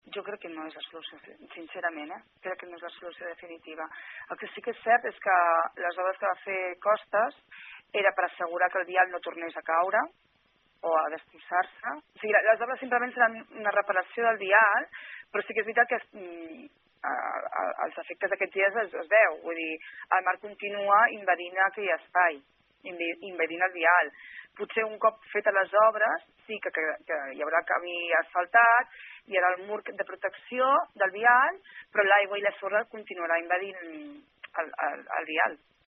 La regidora admet, però, que la solució de millora del vial no és definitiva, ha dit, pel que fa a possibles danys futurs a la zona.